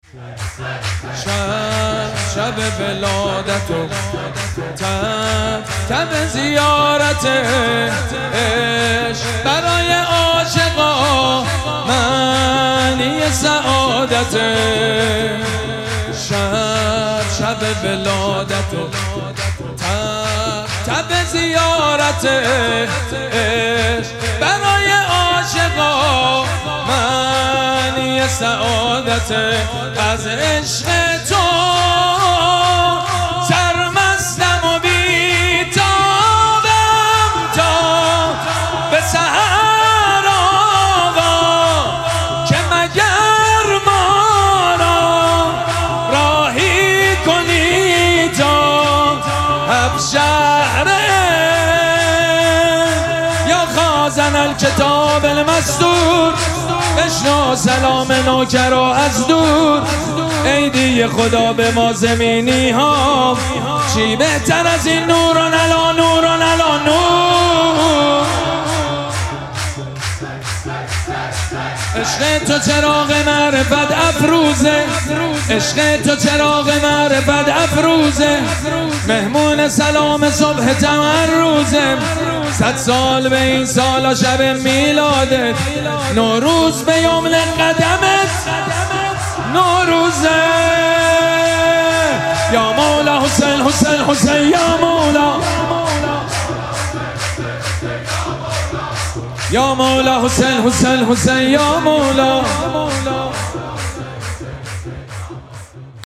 شب اول مراسم جشن ولادت سرداران کربلا
شور
حاج سید مجید بنی فاطمه